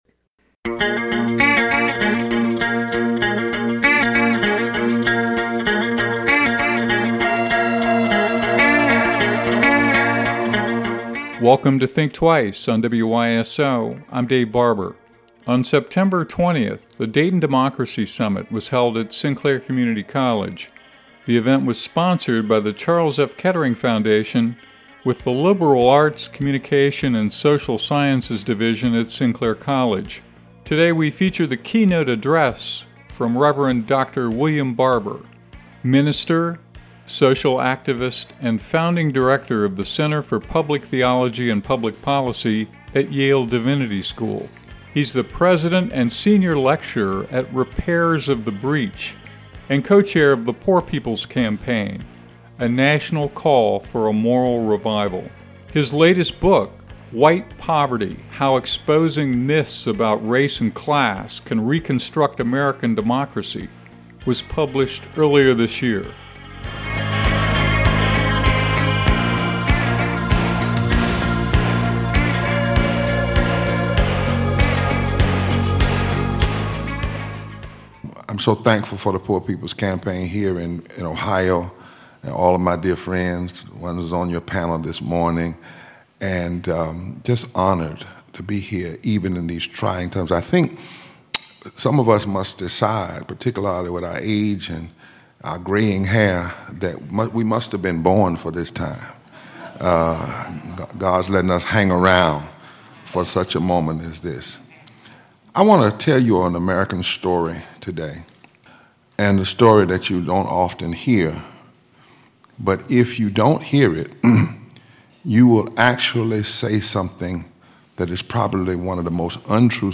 Dr. Barber is co-chair of the Poor People’s Campaign and a senior Kettering Foundation fellow. In September 2024, he spoke at a conference at Sinclair Community College.